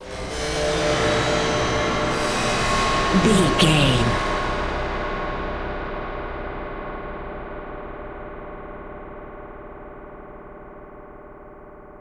Atonal
D
tension
ominous
eerie
synthesiser
ambience
pads